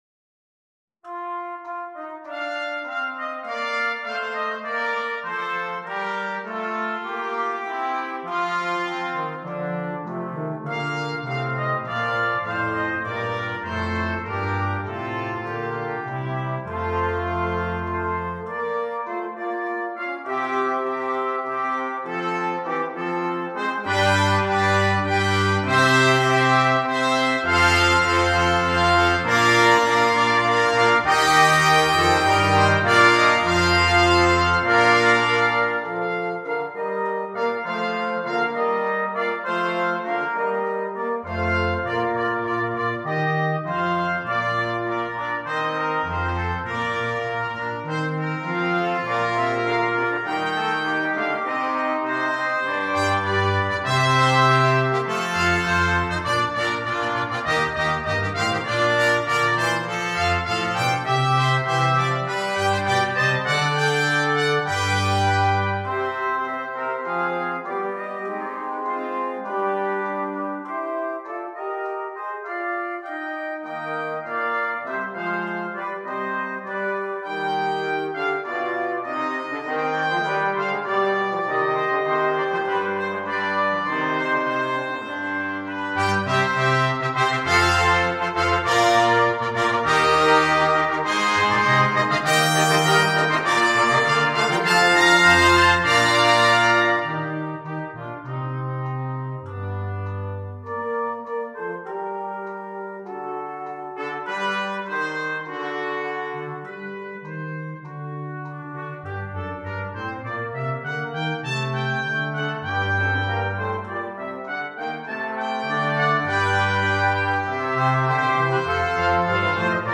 2. Brass Ensemble
10 brass players
without solo instrument
Classical